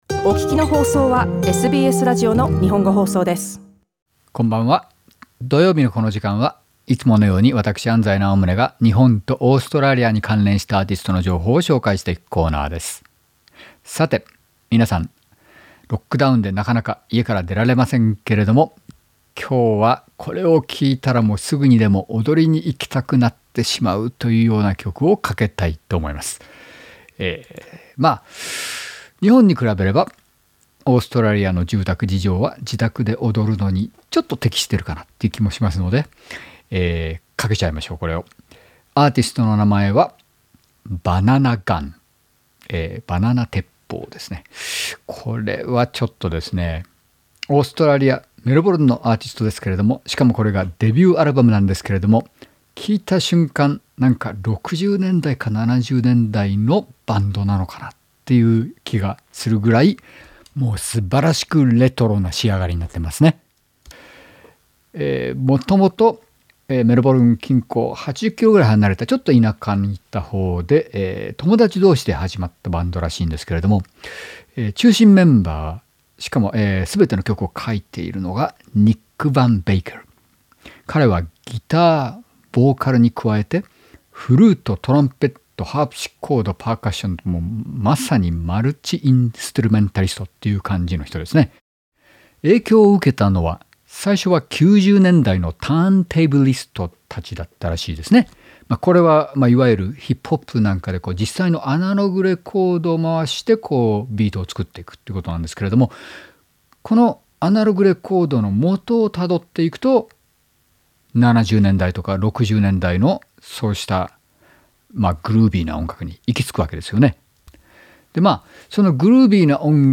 レトロな音楽で90年代かそれ以前の時代の雰囲気があります。